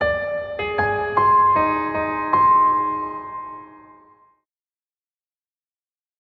Klanglogo Rohfassung
klanglogo_rohfassung.mp3